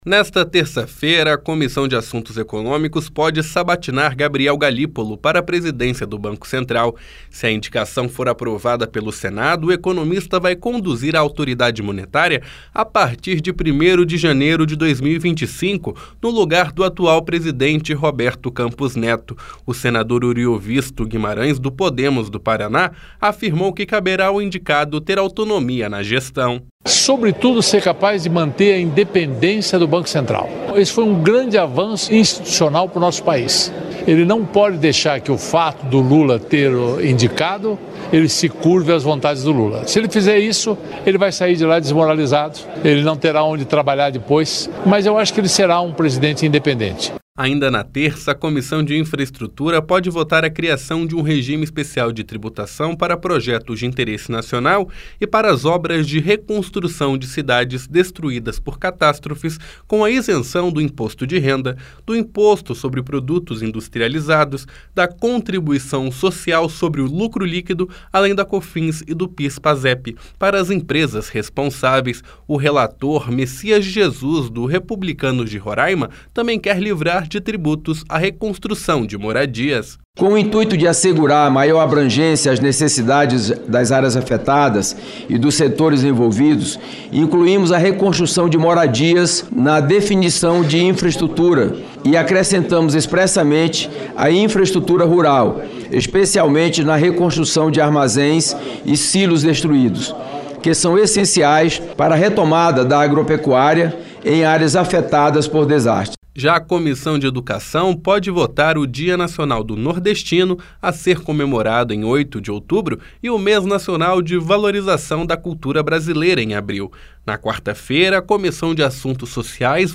Senador Mecias de Jesus
Senador Oriovisto Guimarães